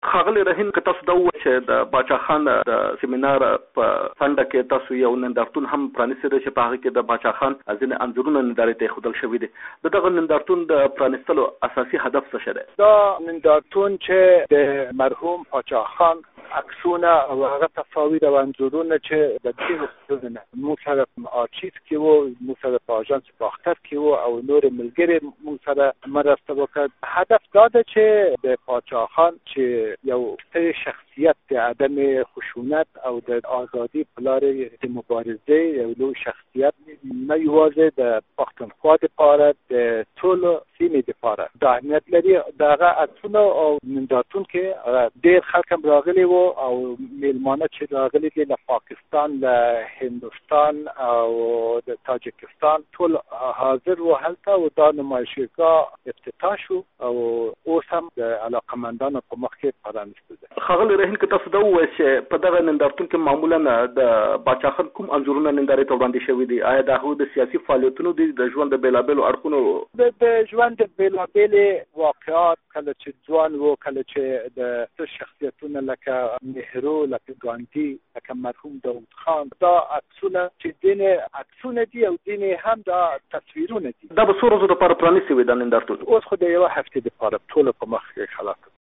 له سید مخدوم رهین سره مرکه